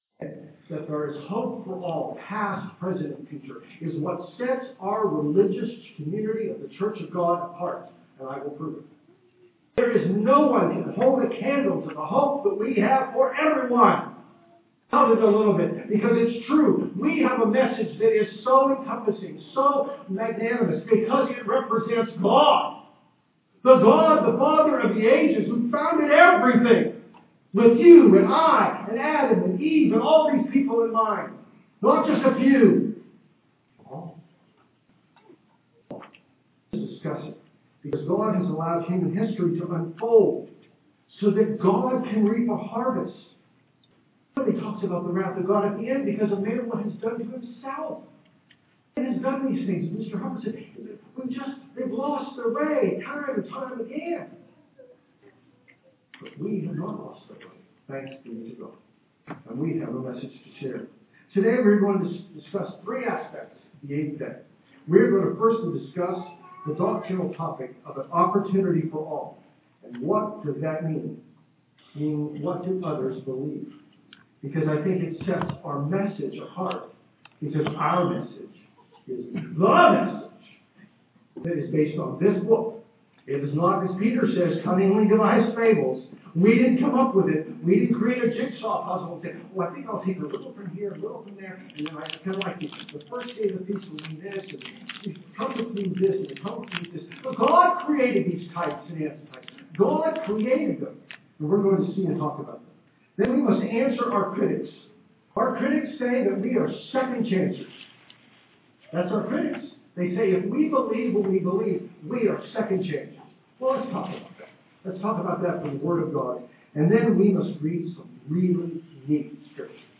This sermon was given at the Galveston, Texas 2016 Feast site.